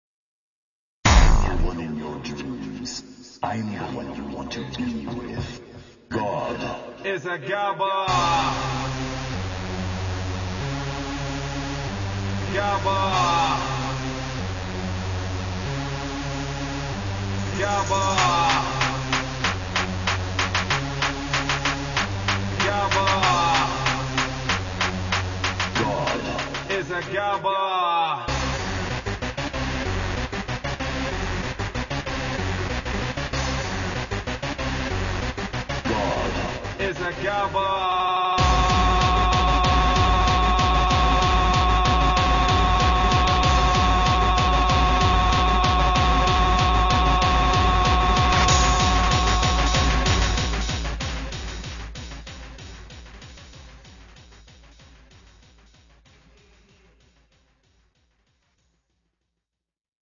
モノラルまで音質を落としています。
またイントロから１分間のみになりますがご了承ください。
GABBAに挑戦。